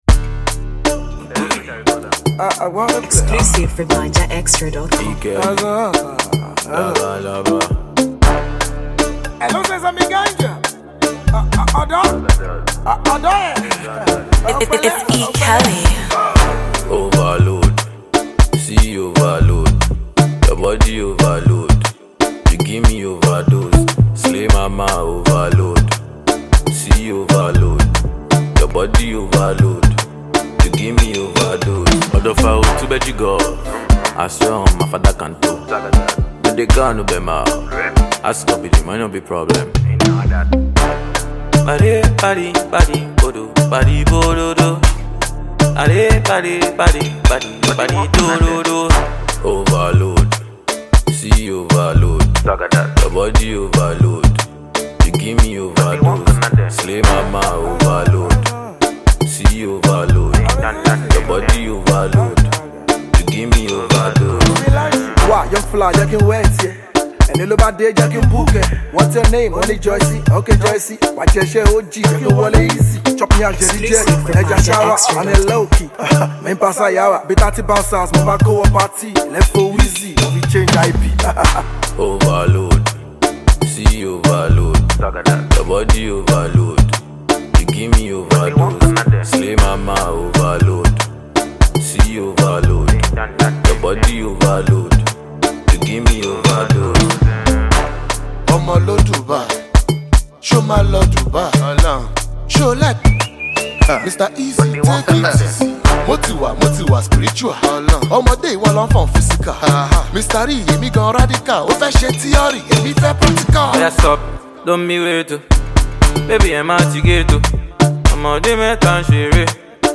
Shaku Shaku vibes